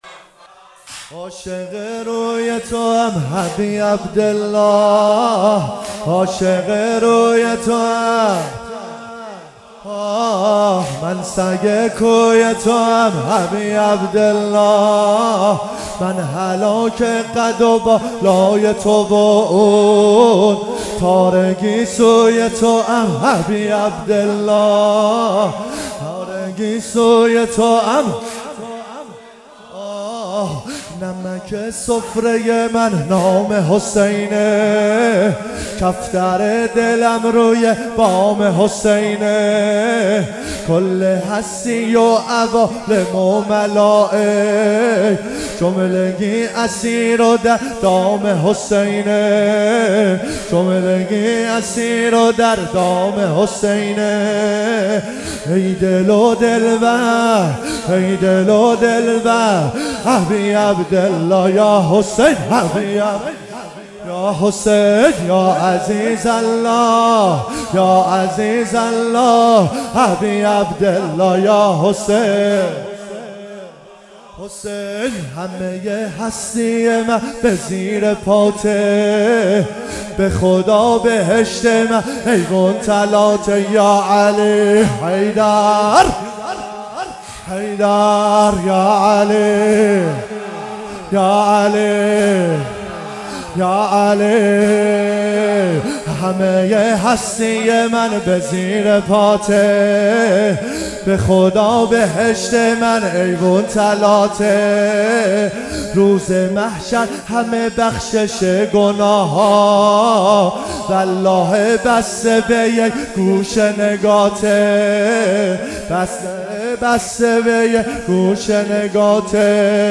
محرم 97